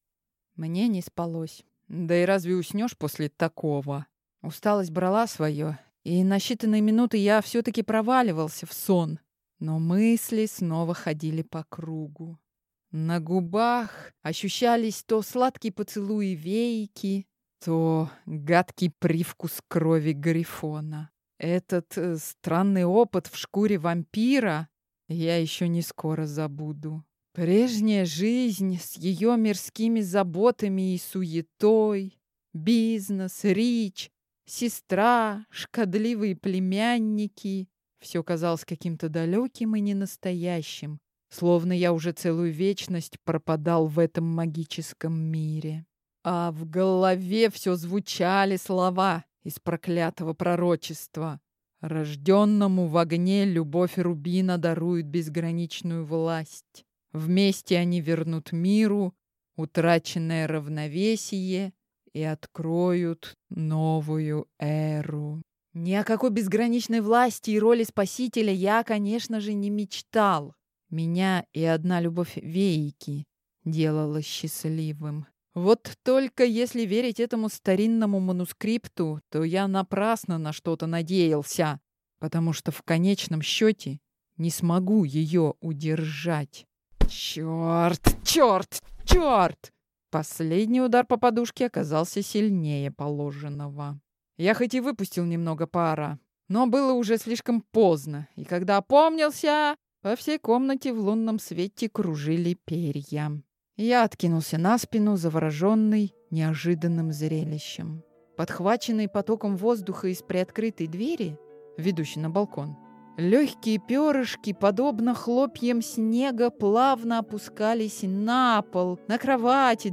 Аудиокнига Дракон в моем сердце | Библиотека аудиокниг